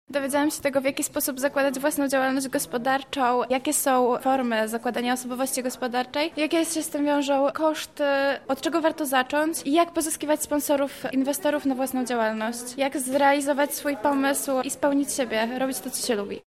Ćwiczenia pozwalały na zdobycie nowej wiedzy – mówi jedna z uczestniczek